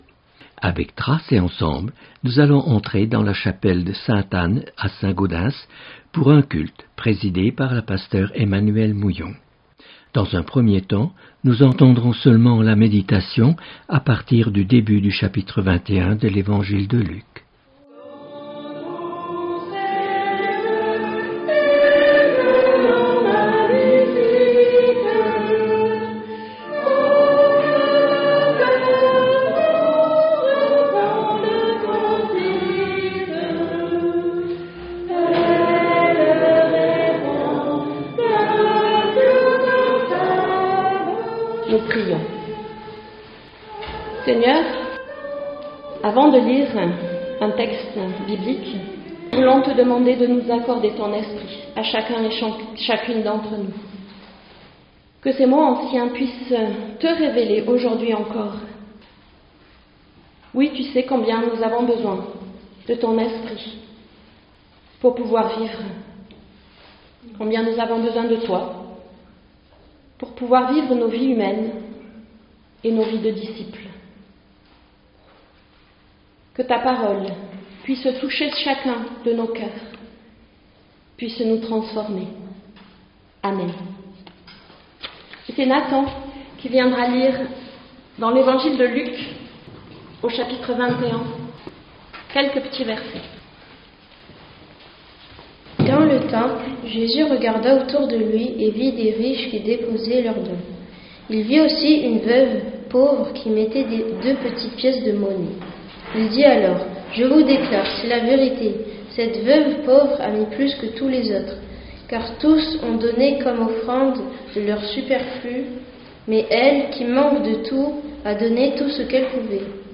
Culte